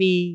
speech
syllable
pronunciation
bi6.wav